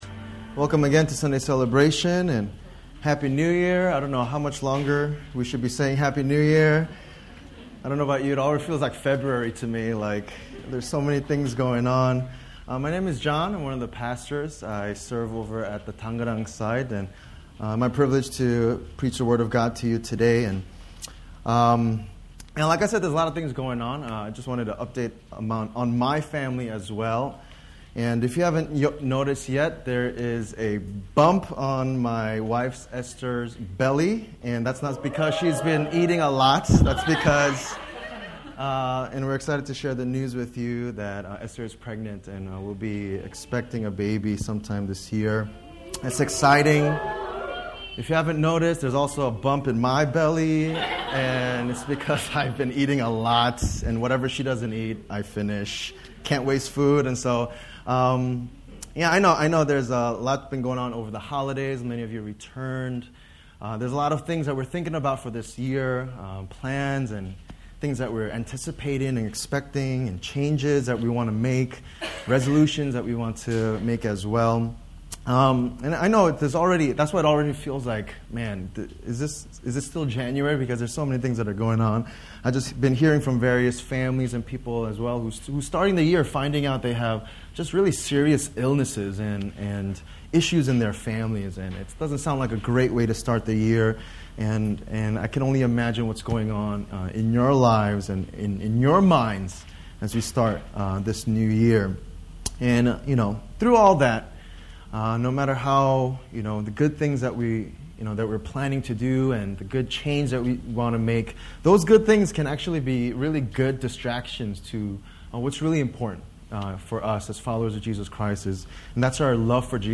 Sermon Summary